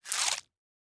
chew.wav